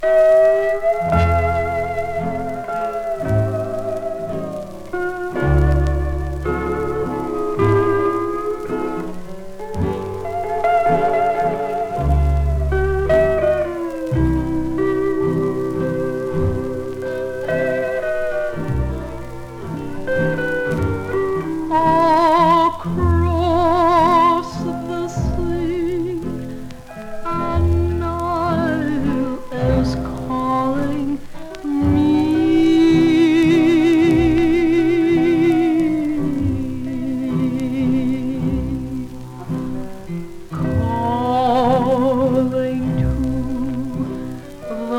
当時のハワイから送られてきた、一瞬でトリップする猛烈な観光地音楽集。
World, Hawaii　USA　12inchレコード　33rpm　Stereo